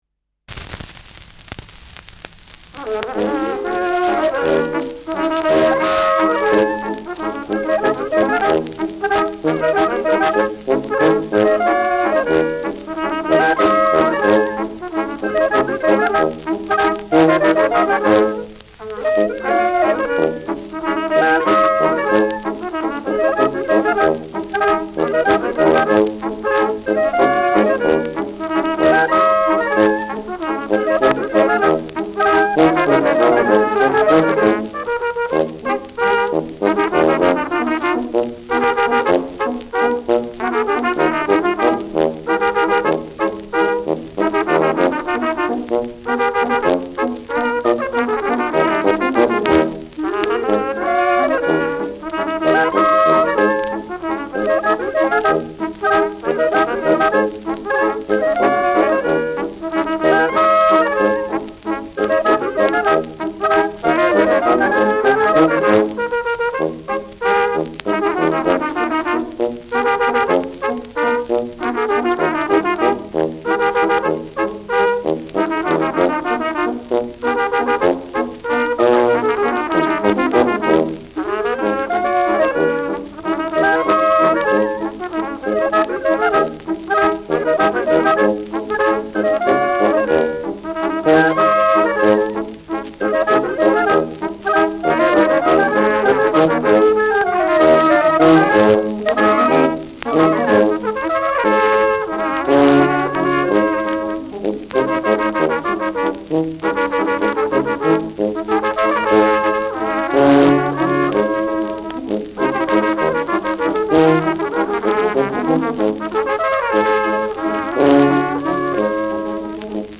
Schellackplatte
leichtes Rauschen : Knistern : gelegentliches „Schnarren“